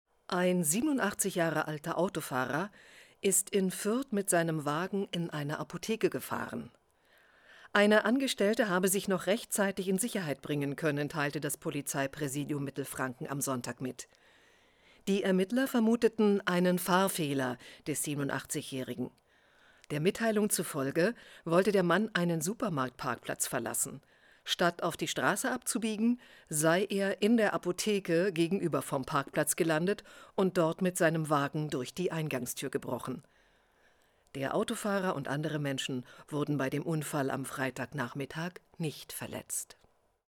Privatsender
Nachrichten